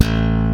ALEM SLAP A1.wav